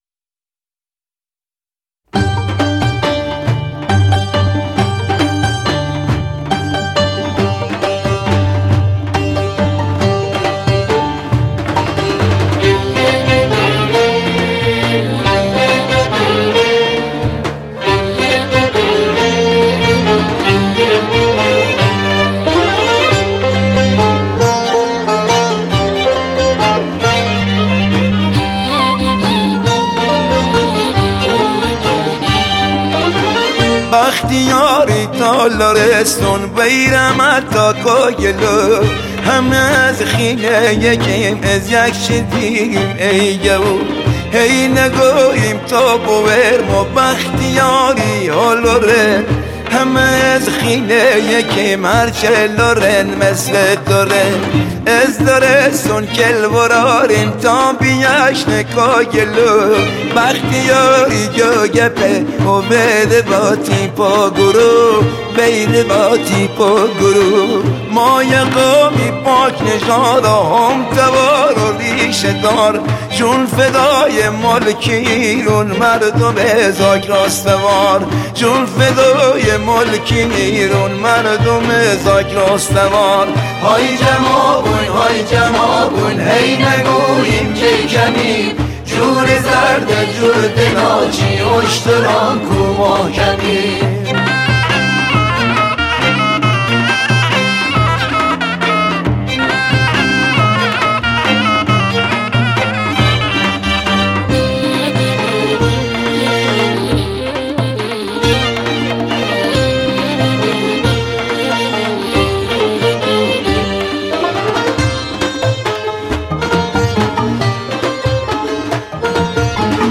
Lori Song